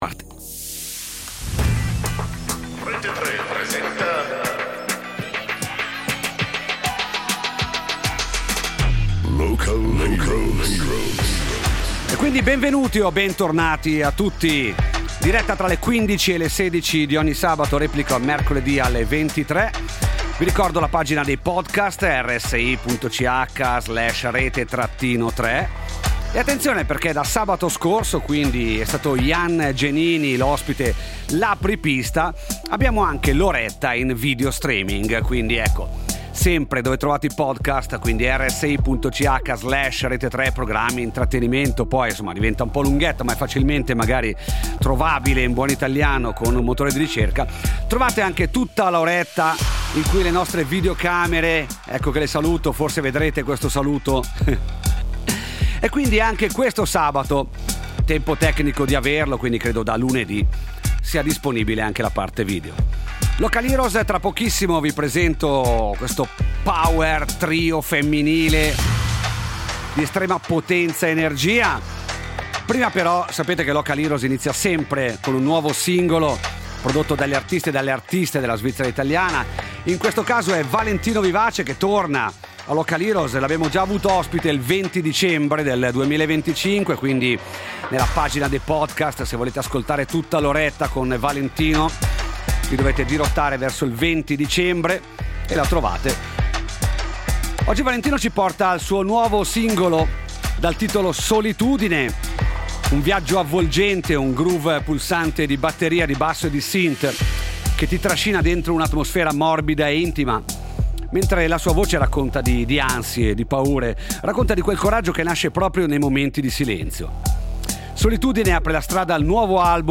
Local Heroes Black Heidis 14.03.2026 55 min RSI - Rete Tre Contenuto audio Disponibile su Scarica Con le Black Heidis lo studio esplode di energia! Power‑trio tutto al femminile attivo dal 2007
mix potentissimo di rock, groove e personalità